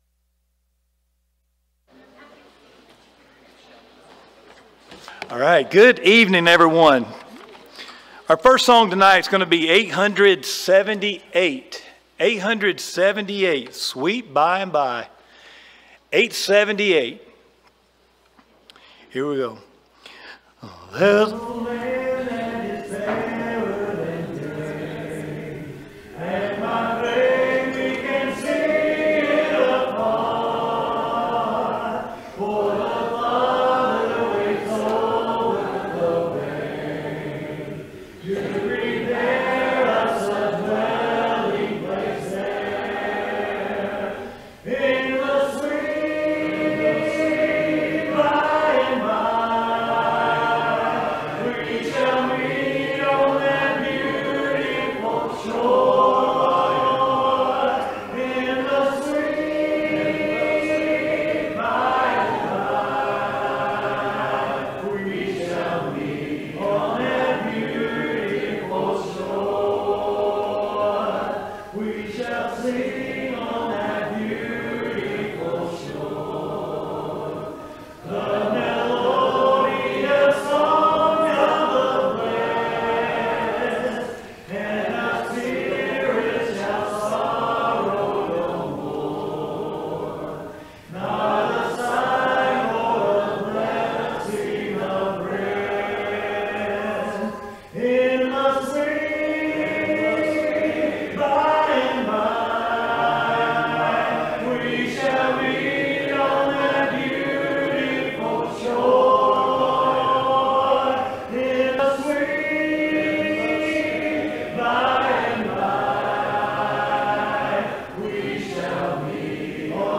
Series: Sunday PM Service